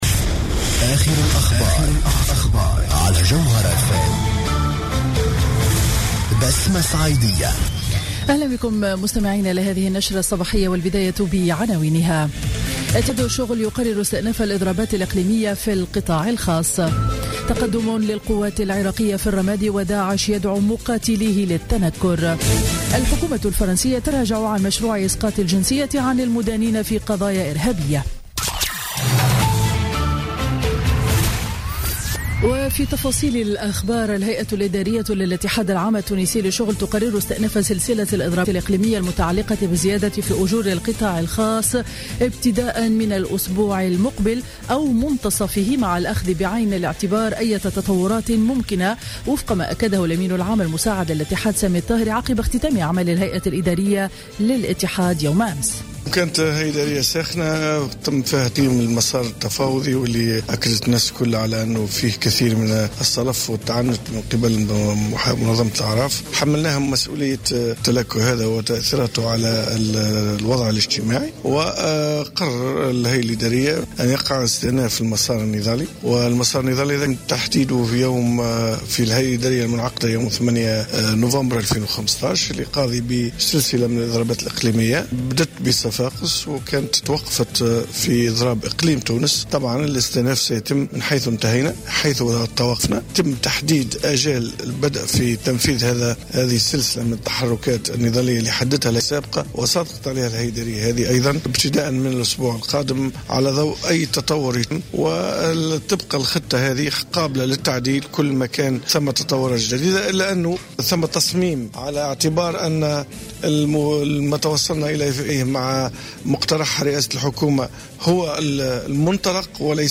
نشرة أخبار السابعة صباحا ليوم الأربعاء 23 ديسمبر 2015